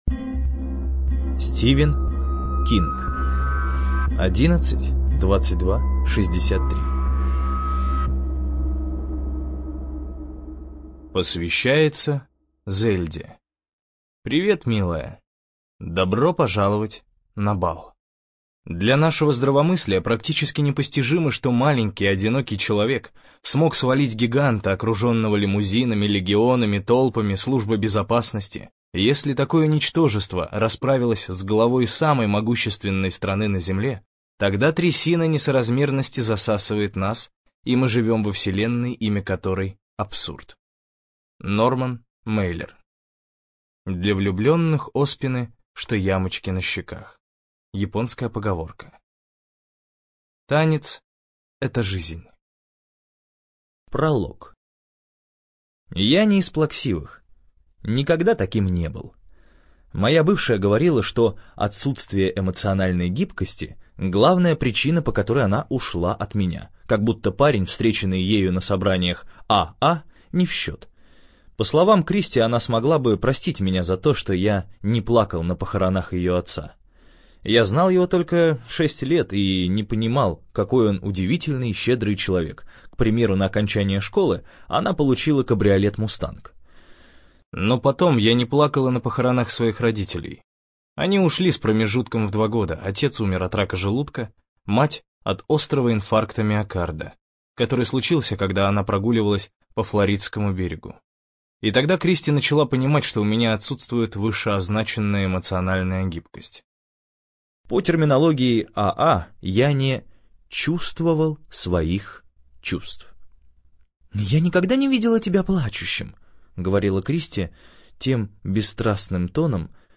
ЖанрАльтернативная история